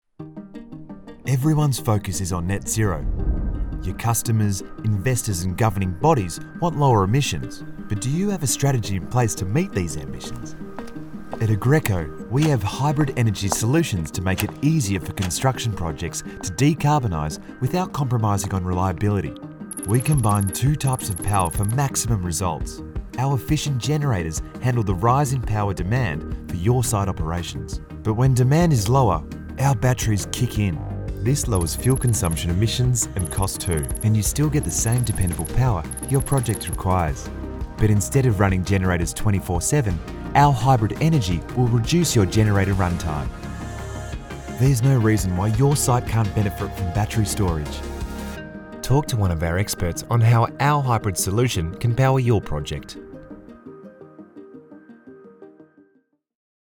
Vídeos Explicativos
Olá, sou uma dubladora profissional de inglês australiano com uma entrega calorosa, clara e versátil.
Inclui um microfone Rode NT1-A e uma interface de áudio, tudo instalado em uma cabine à prova de som para garantir excelente qualidade de gravação.